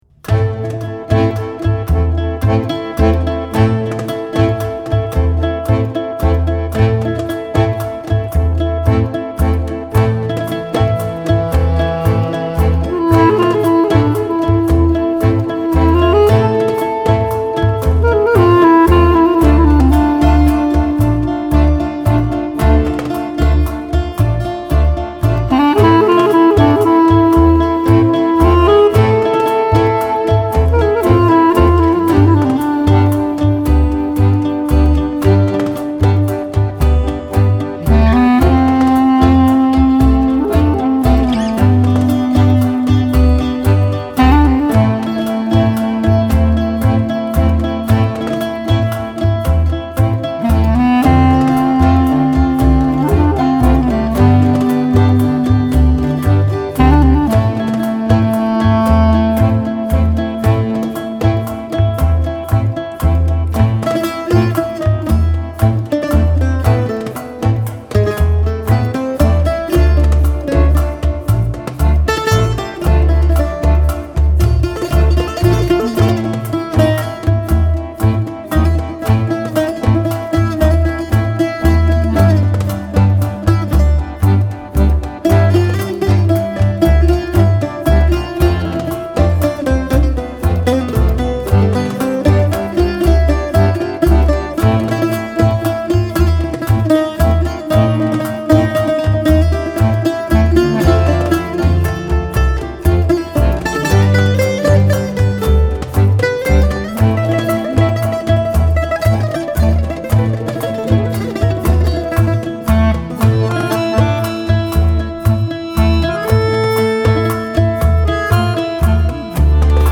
薩克斯風、單簧管及其它樂器
吉他及其它樂器
手風琴、鋼琴及其它樂器
貝斯、打擊樂器及其它樂器